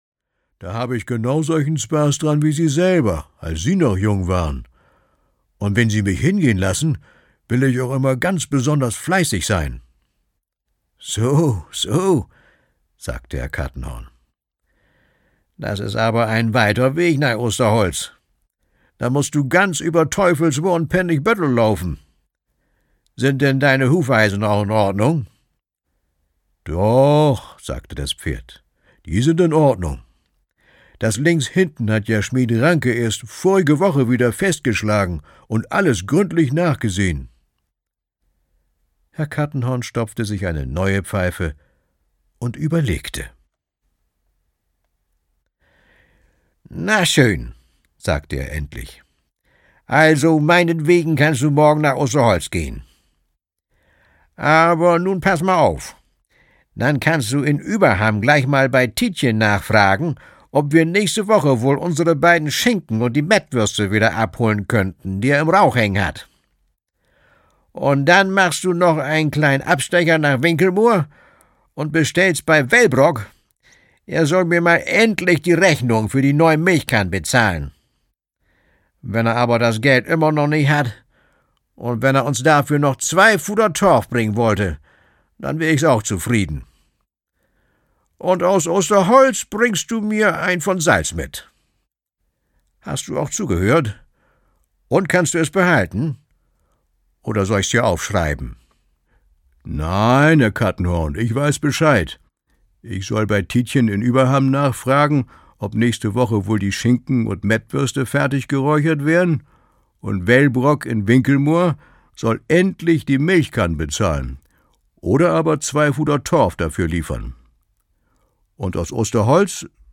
Kattenhorns Pferd - Fabeleien um das alte Worpswede von Fritz Theodor Overbeck - Fritz Theodor Overbeck - Hörbuch